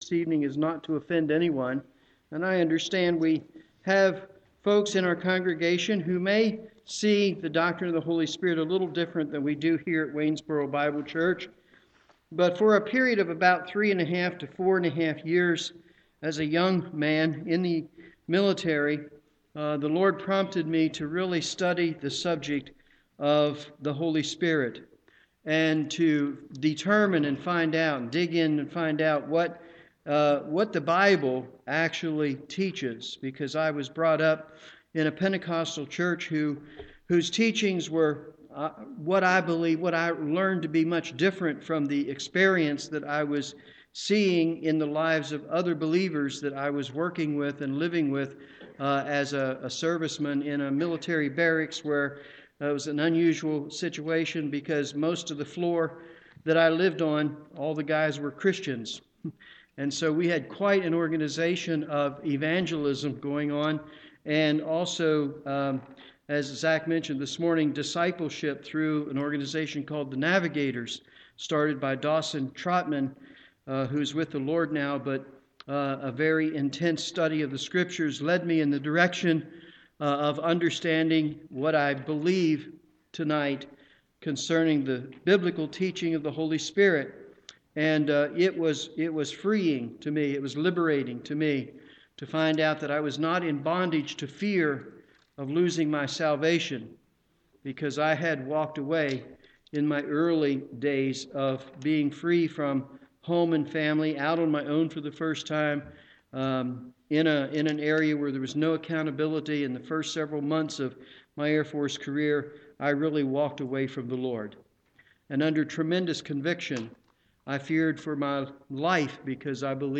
Pneumatology: The Person and Work of God the Spirit - Waynesboro Bible Church